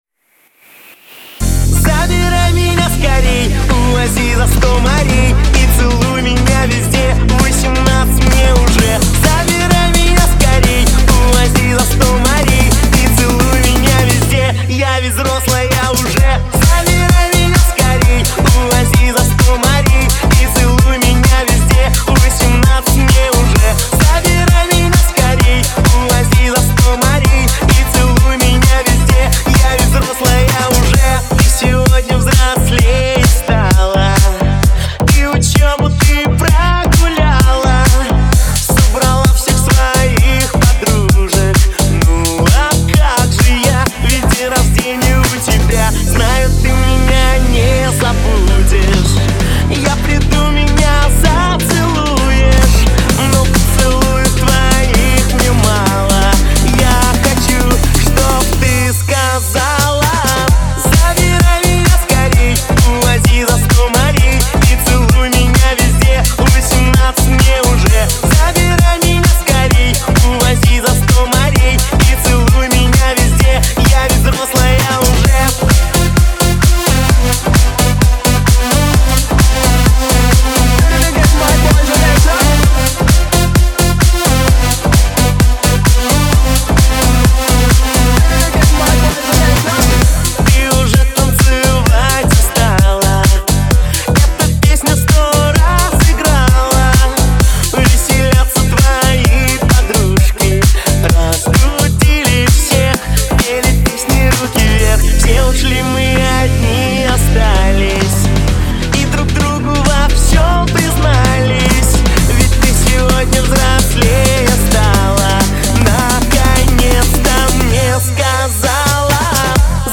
Жанр: Pop & Music & Singer | Год: 2026